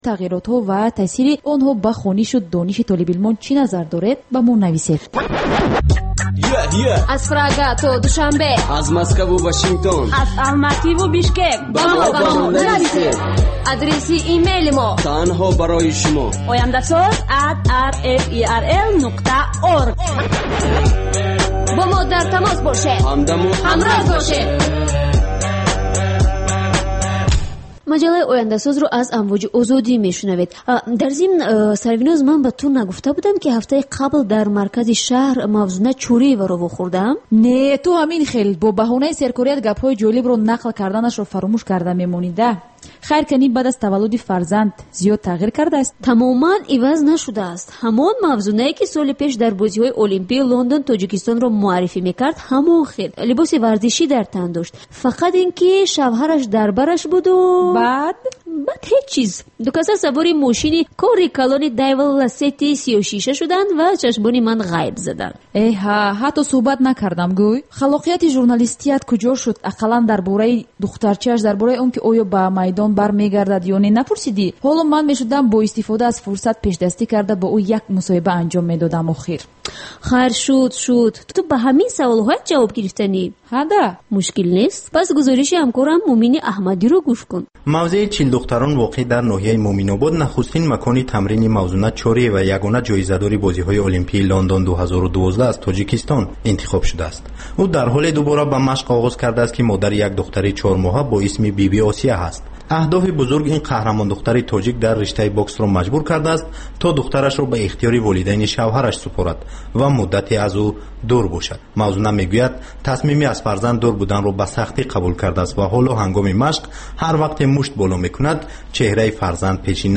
Дин ва ҷомеа. Гузориш, мусоҳиба, сӯҳбатҳои мизи гирд дар бораи муносибати давлат ва дин.